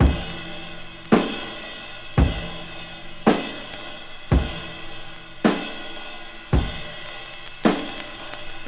• 110 Bpm Modern Drum Loop F# Key.wav
Free breakbeat - kick tuned to the F# note. Loudest frequency: 1442Hz
110-bpm-modern-drum-loop-f-sharp-key-pcs.wav